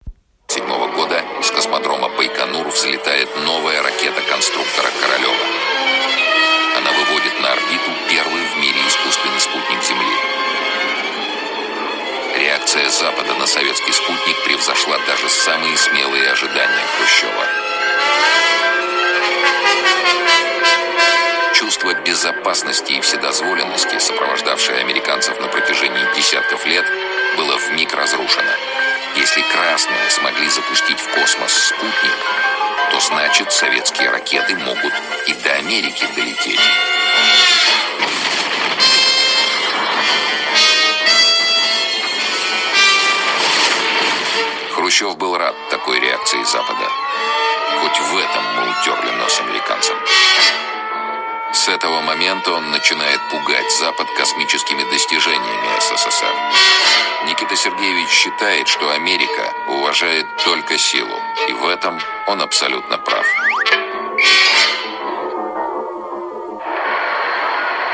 Автор топика предлагает индентифицировать фоновую музыку.